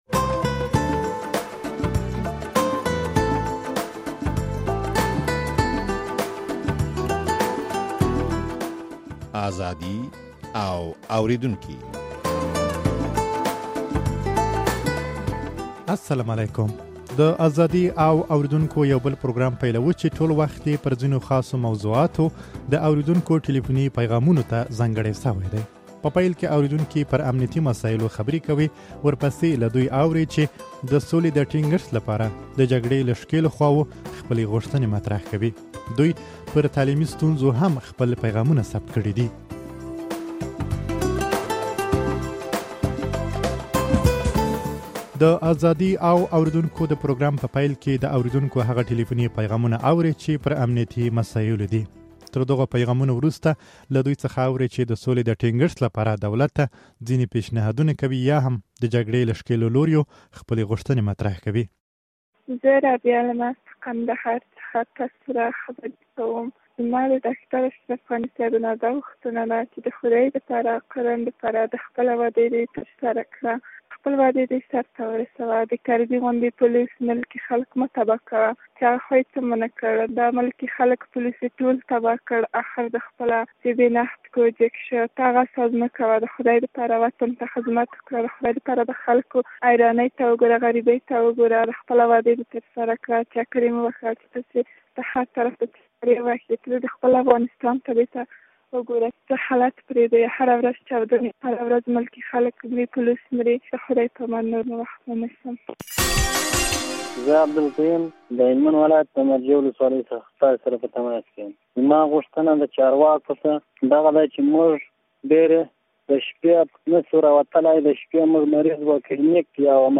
د ازادي او اورېدونکو يو بل پروګرام پيلوو، چې ټول وخت يې پر ځينو خاصو موضوعاتو د اورېدونکو ټليفوني پيغامونو ته ځانګړى شوى دى.